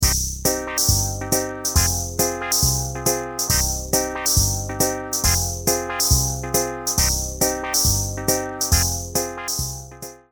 Orgue classique avec boite à rythme intégrée.
Sound demo